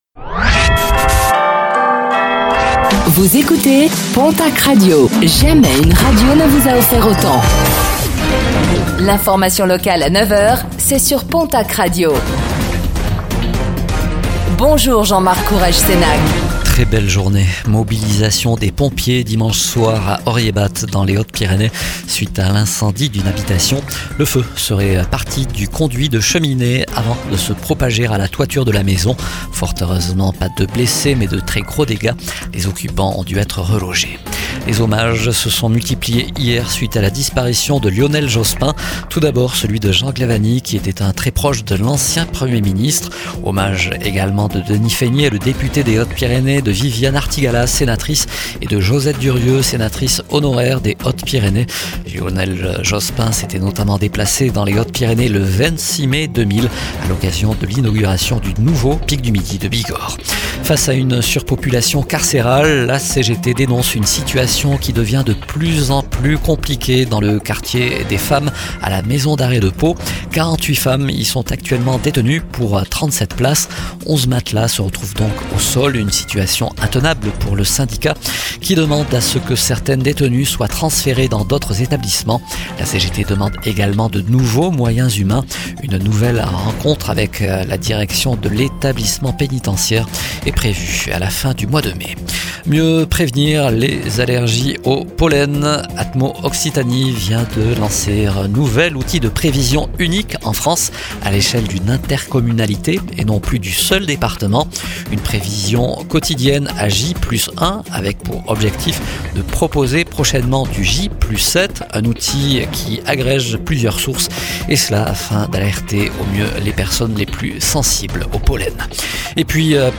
09:05 Écouter le podcast Télécharger le podcast Réécoutez le flash d'information locale de ce mardi 24 mars 2026